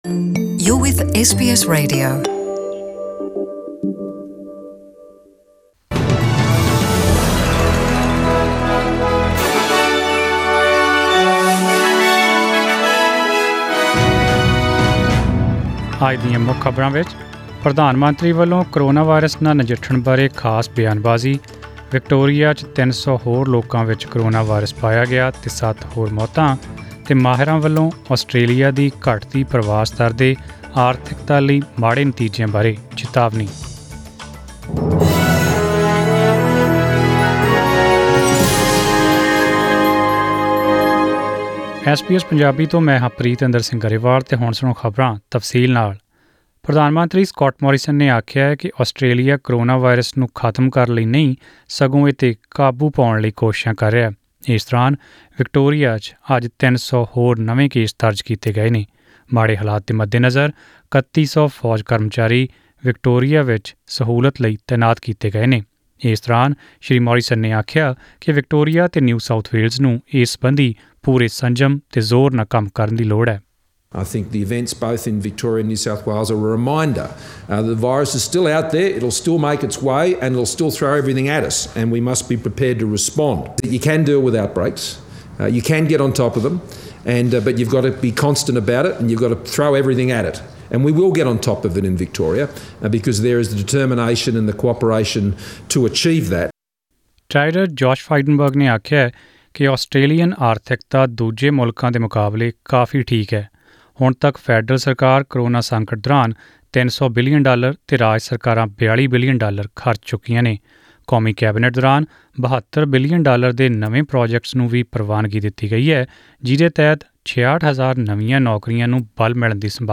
Australian News in Punjabi: 24 July 2020